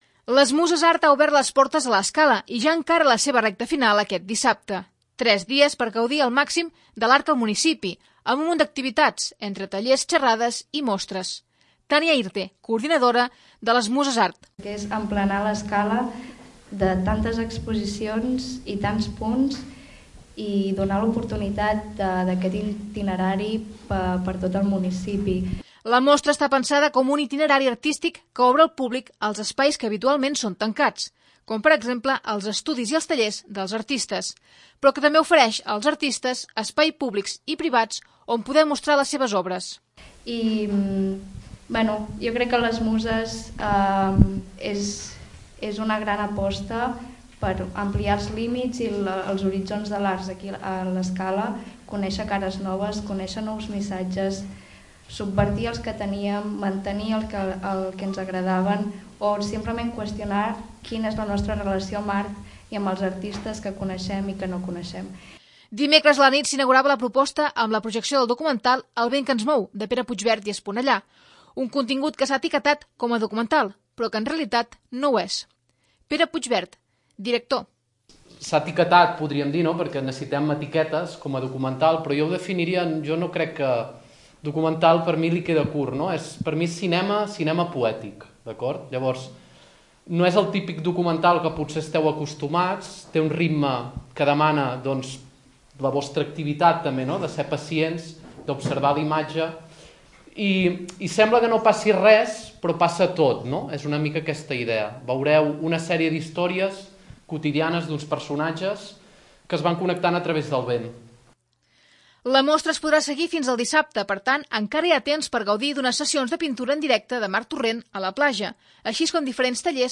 Així ho explicava el regidor de patrimoni, Martí Guinart, a l'entrevista setmanal de La Casa de la Vila.